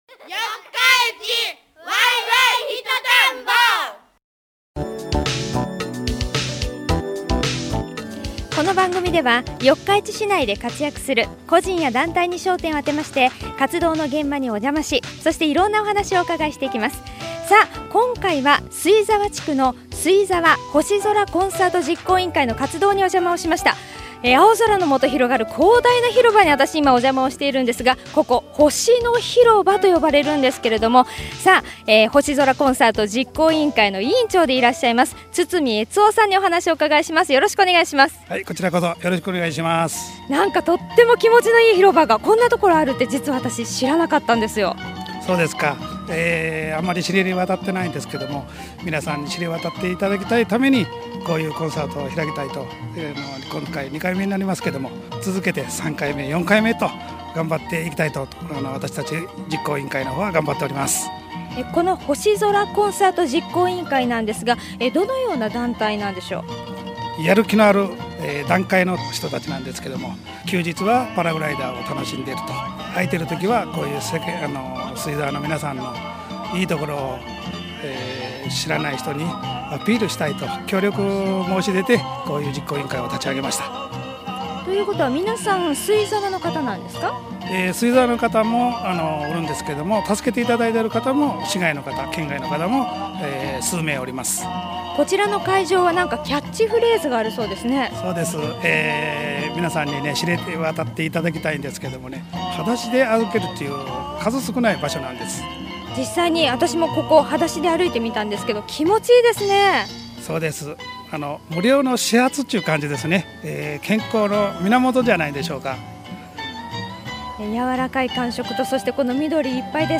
…四日市で生き生きと活動している人の活動現場の声をお届けします。